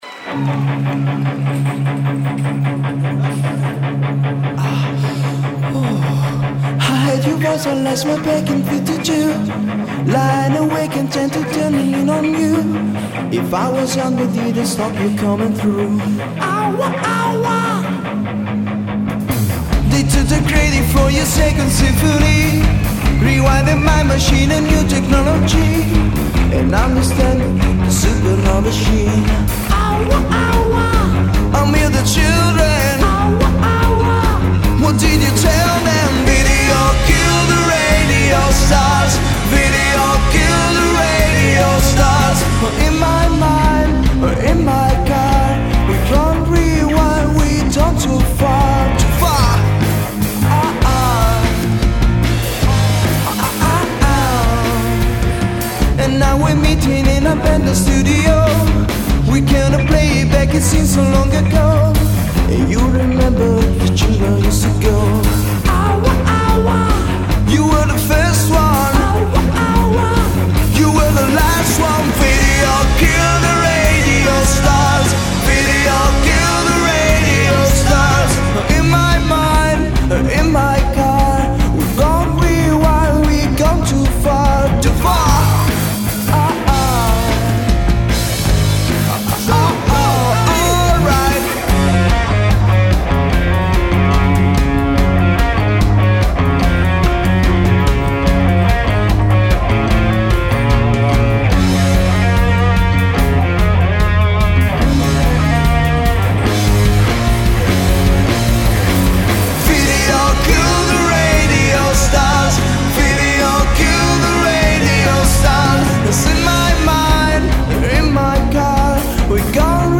Voce, Chitarra Acustica
Voce, Basso
Chitarre
Batteria
Cover pop-rock